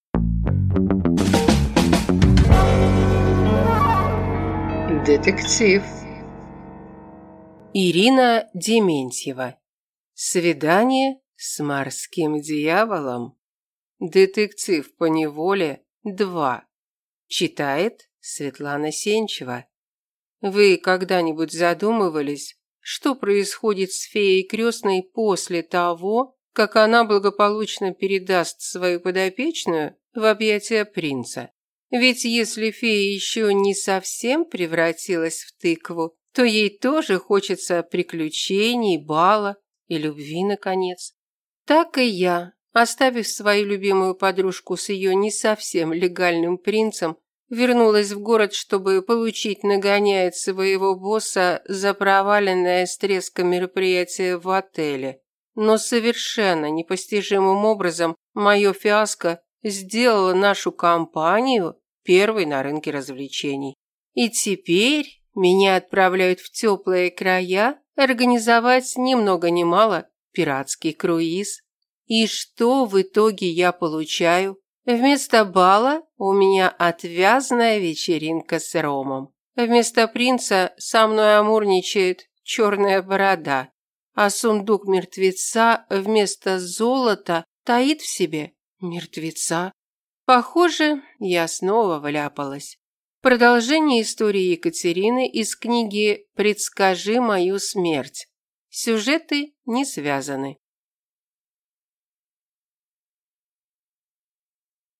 Аудиокнига Свидание с морским дьяволом | Библиотека аудиокниг